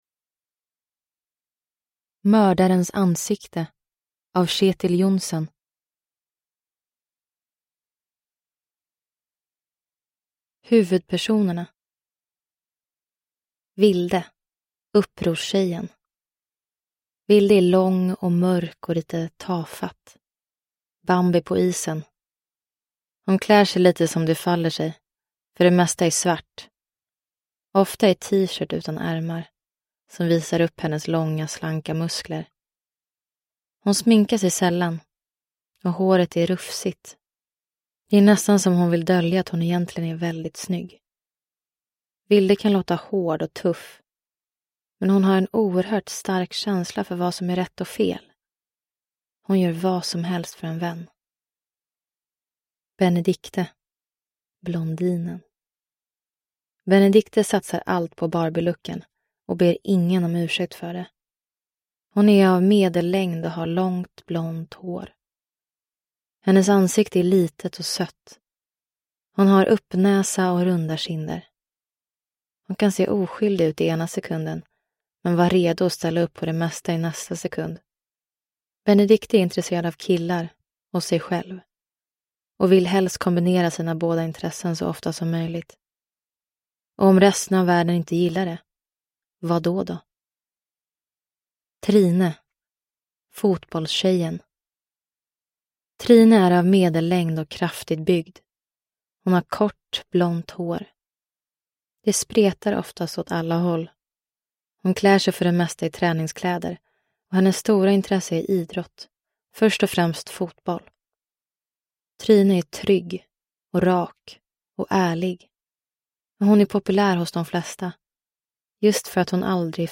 Mördarens ansikte – Ljudbok